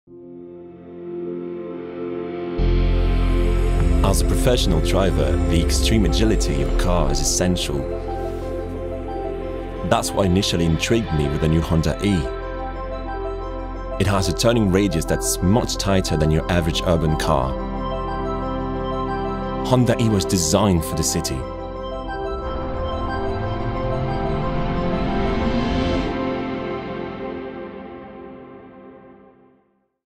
HONDA ENG (VOICE OVER - GERMANY)